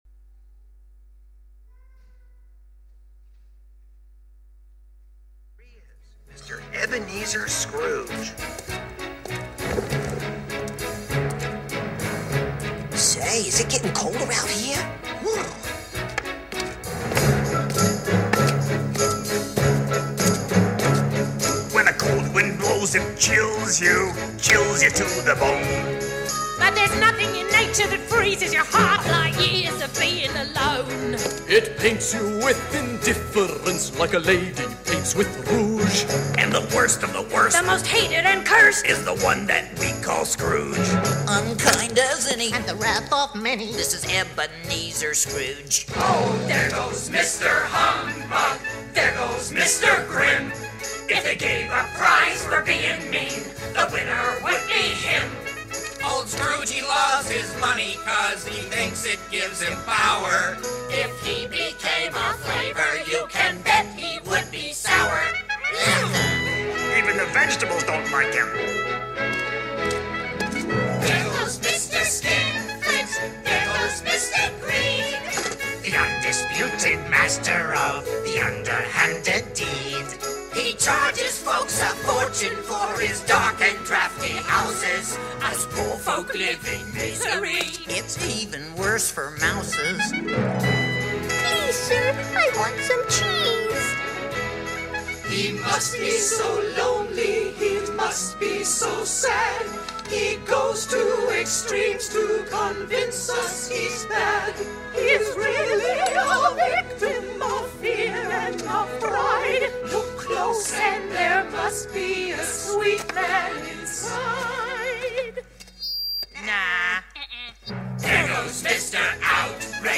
1 Samuel 6:1-7:17 Service Type: Morning Service « 2 Corinthians 8:1-15